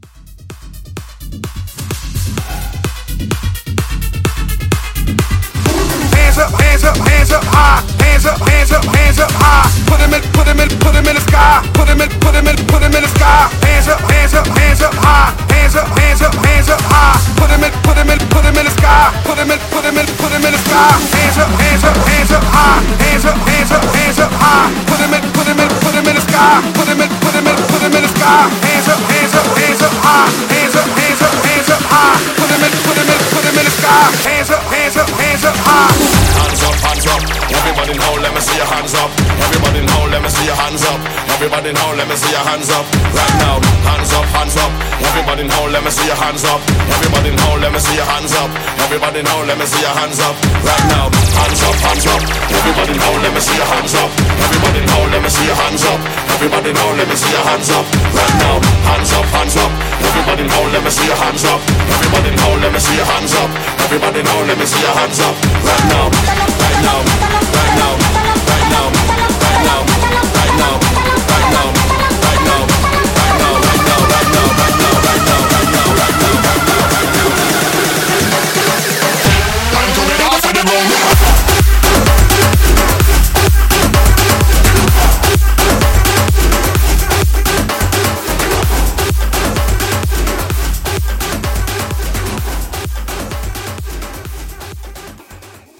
RE-DRUM , ROCK 113 Clean